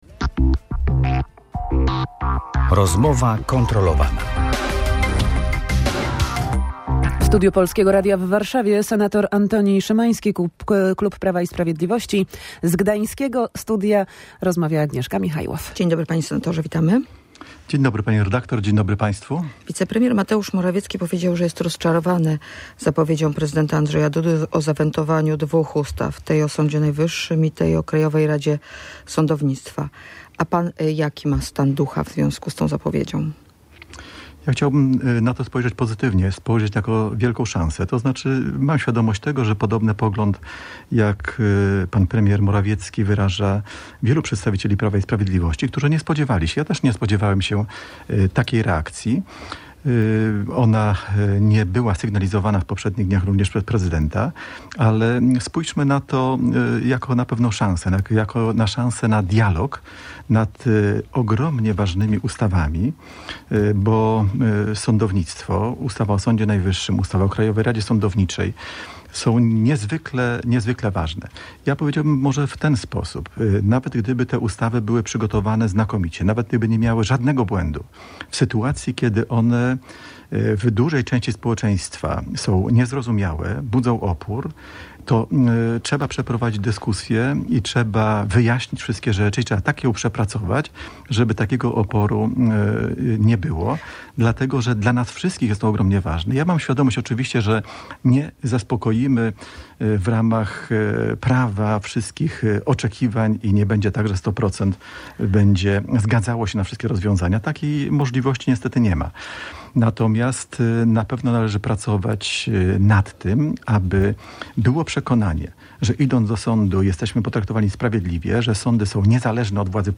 – To okazja do dialogu ze społeczeństwem, trzeba przeprowadzić dyskusję i wyjaśnić wszystkie wątpliwości – tak o decyzji zawetowania ustaw o SN i KRS przez prezydenta Andrzeja Dudę mówił w Rozmowie kontrolowanej Antoni Szymański, senator Prawa i Sprawiedliwości.